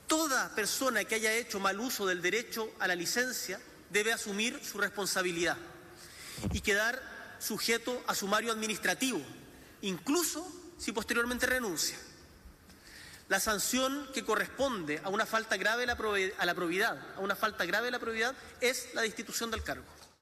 cuna-tl-discurso-boric-licencias.mp3